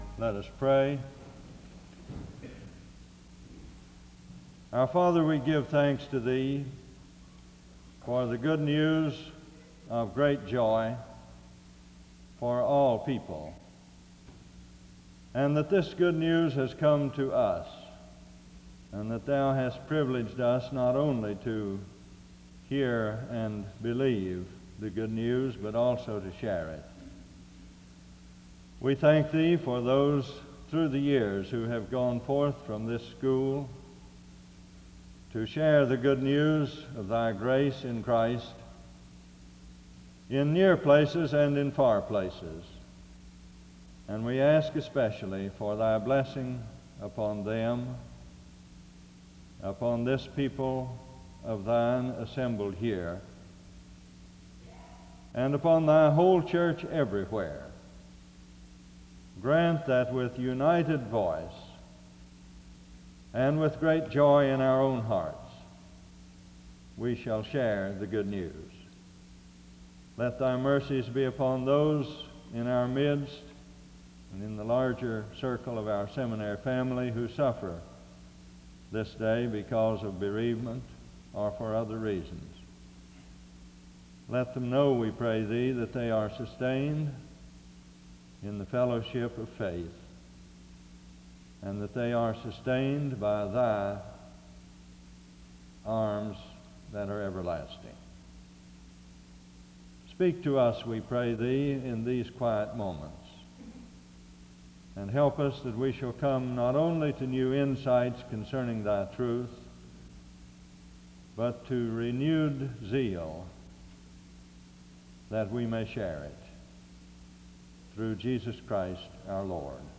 The service starts with a word of prayer from 0:00-2:01.
A special song by the choir is performed from 4:25-6:26.
The service closes with music from 33:02-33:38.